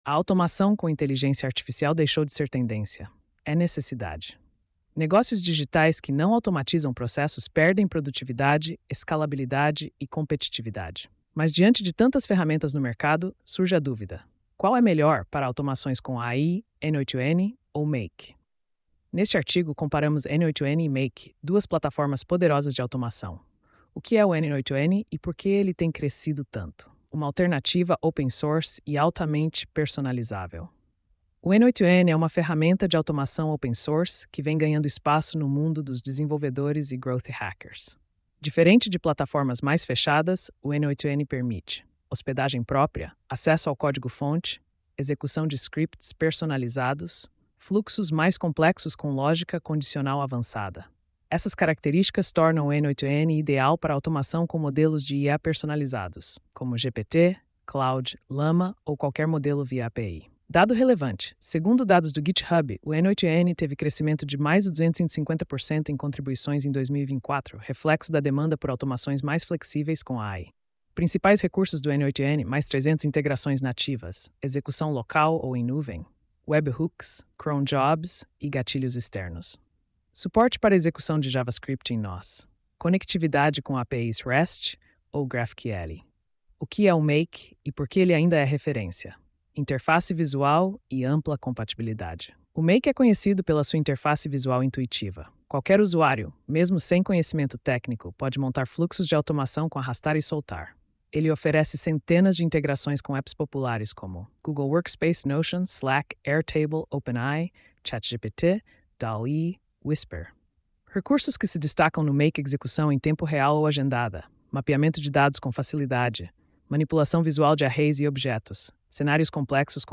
post-2959-tts.mp3